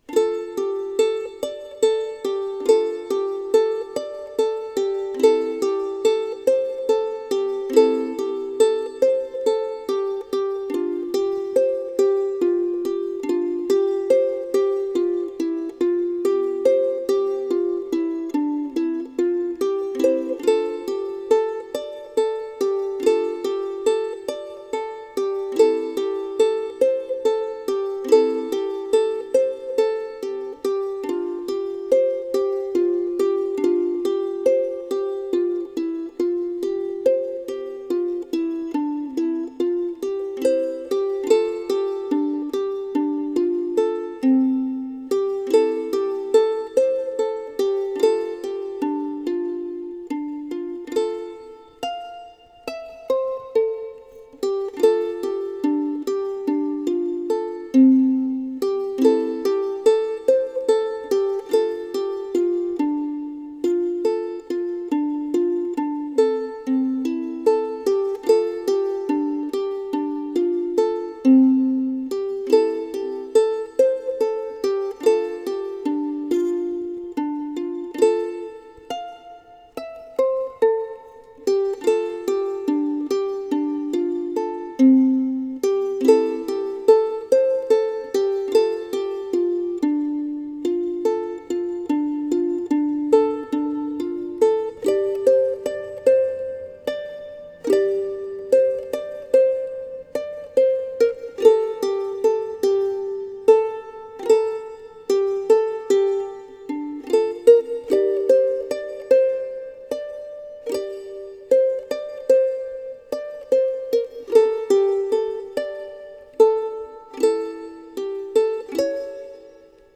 昨年のクリスマス向けにアレンジした曲ですが、今回はソロアレンジにしてみました。